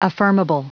Prononciation du mot affirmable en anglais (fichier audio)
Prononciation du mot : affirmable